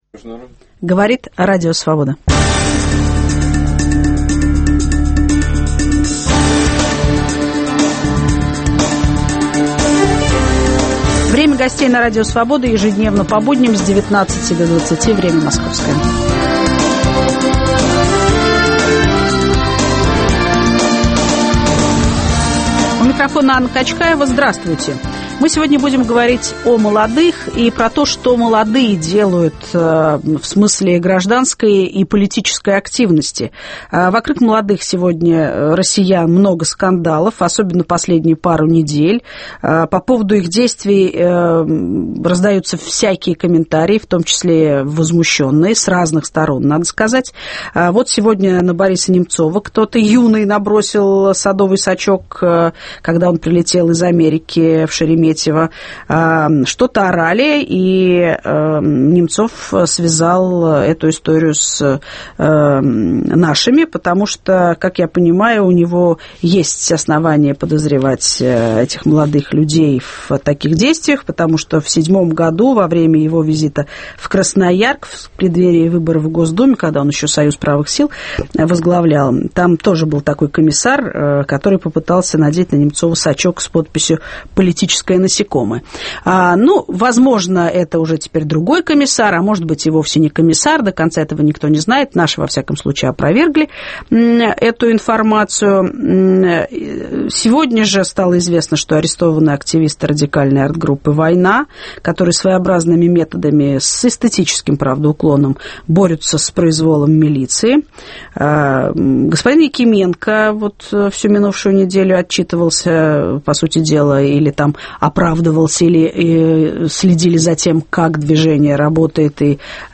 В студии писатель Сергей Шаргунов, режиссер Павел Бардин, публицист и телеведущий Александр Архангельский.